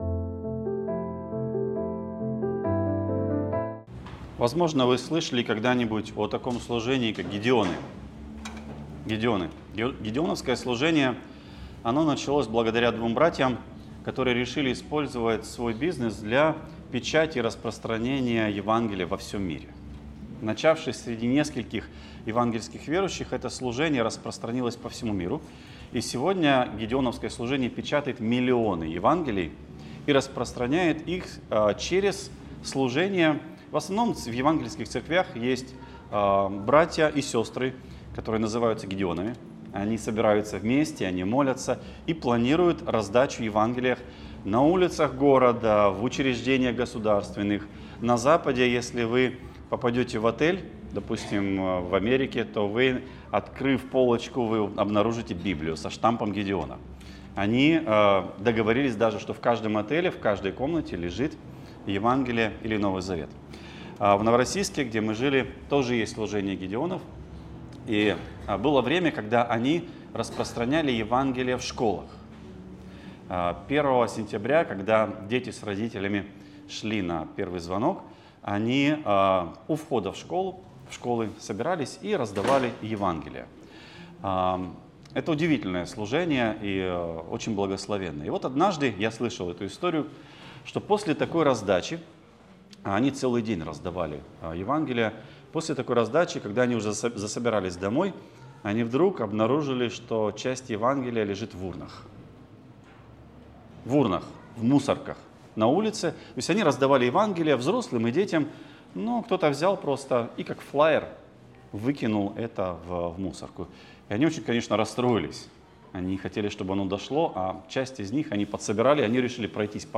Не мечите бисер перед свиньями Проповедник